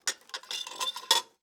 SFX_Plates+Utensil_01.wav